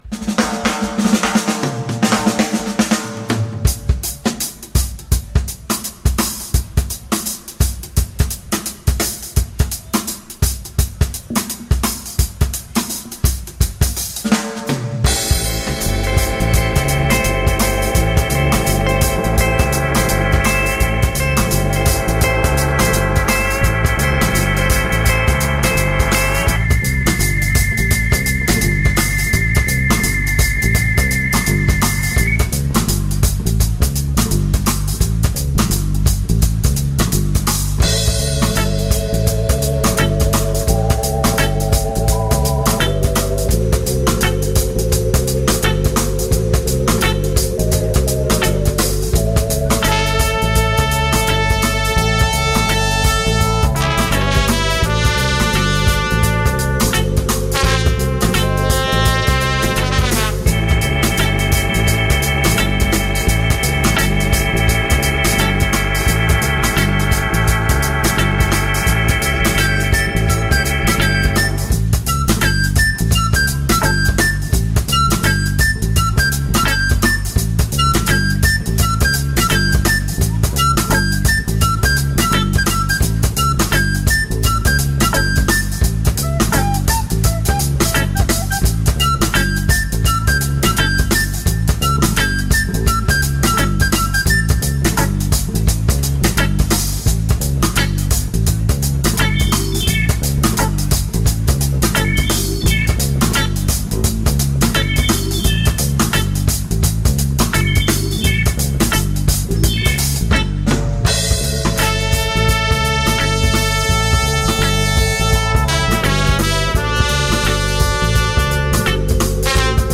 in concert from Rotherham 2002
Indie and Jazz/ Funk .